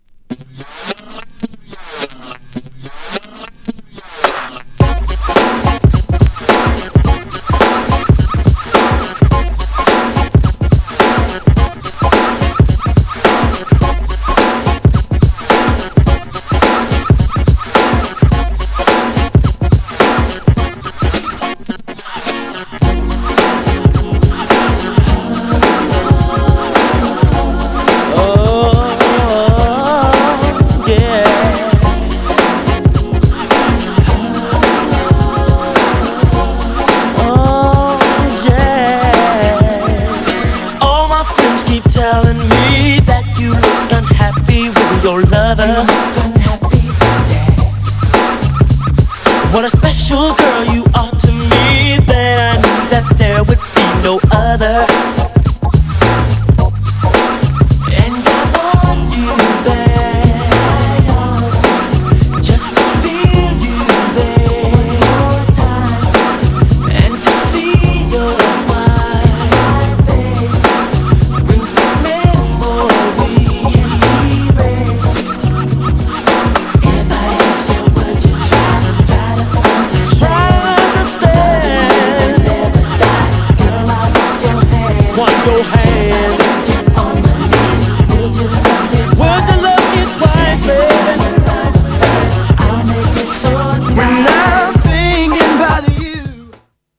男性グループによるメロウ＆グルービーな90's R&B!!
GENRE R&B
BPM 71〜75BPM
# NEW_JACK
# SMOOTH_R&B # ちょいハネ系 # スロー # メロウ # 男性コーラスR&B